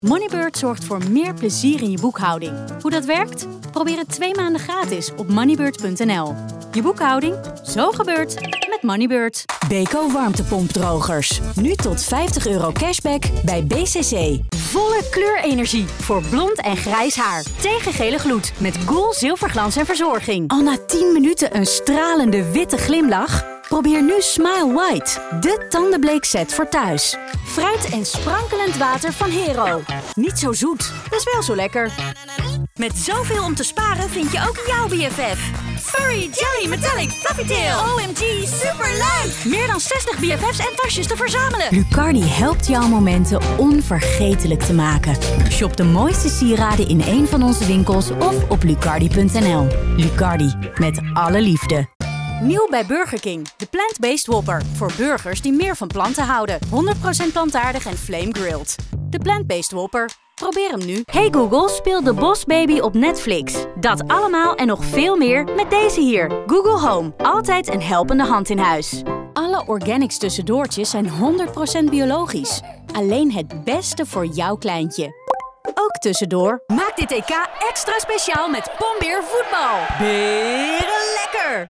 Enthousiaste
Croyable
Dynamique